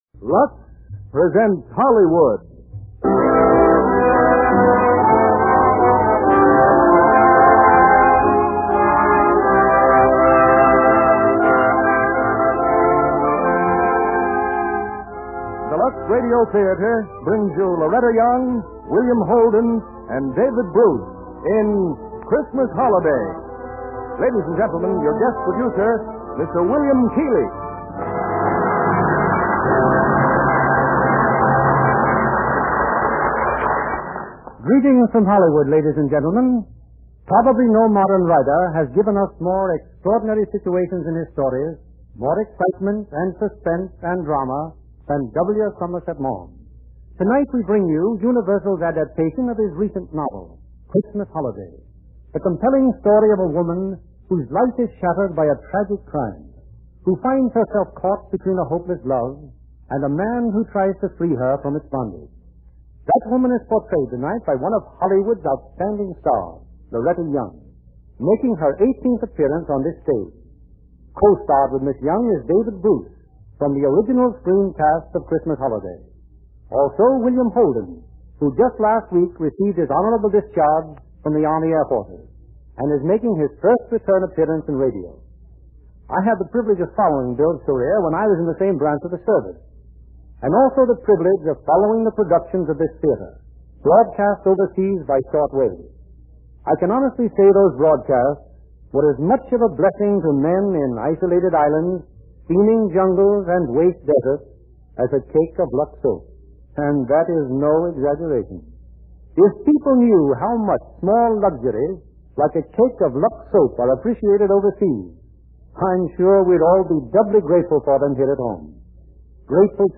starring Loretta Young, William Holden, David Bruce